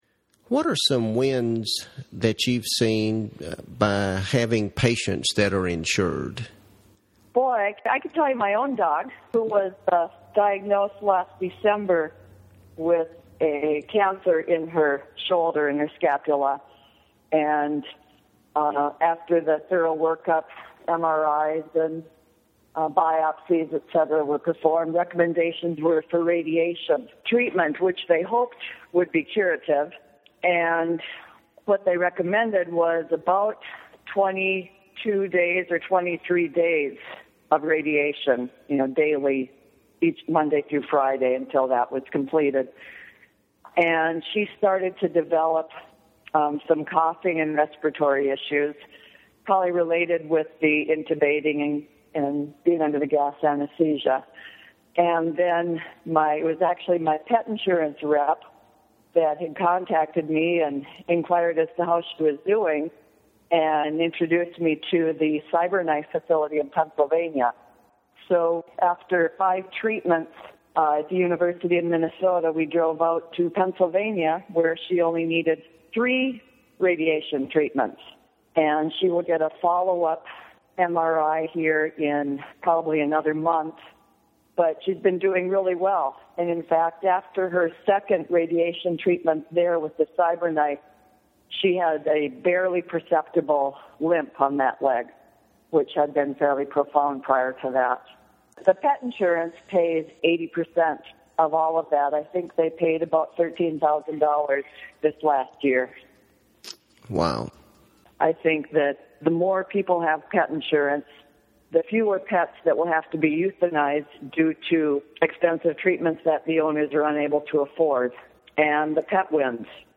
Her dog was able to tolerate the much shorter treatment regimen with perhaps an even better outcome. Listen as she tells the story: